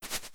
Grass.wav